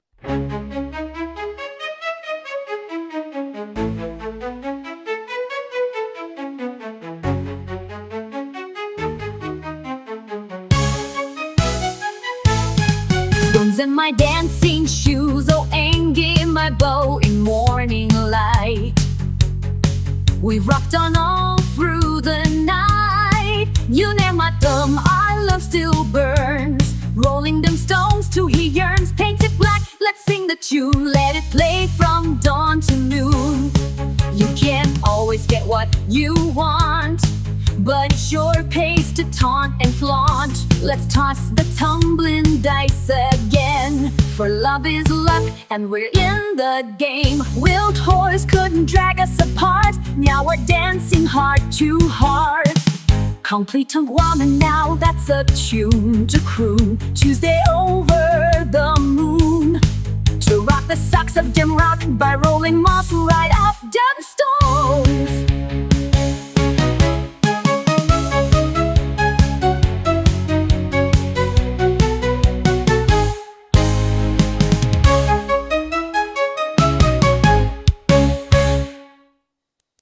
Hear it set to music - rock style.